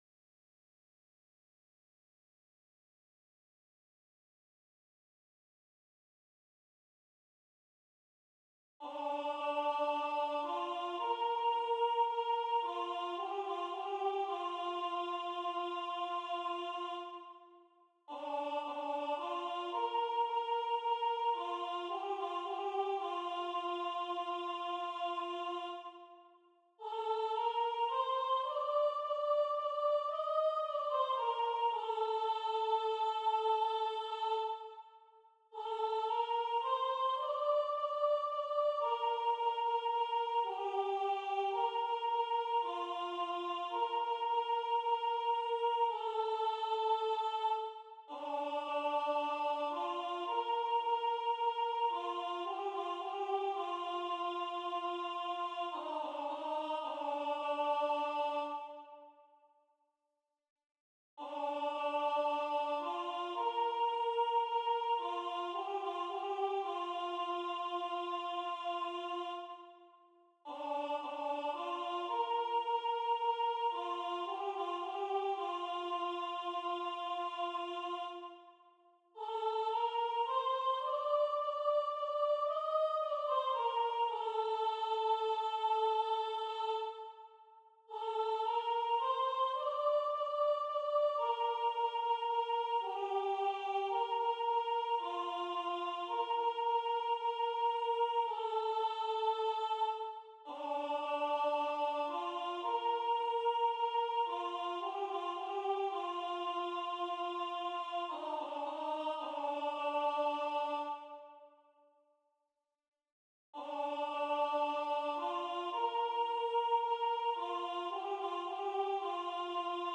à 3 voix mixtes
MP3 rendu voix synth.
Soprano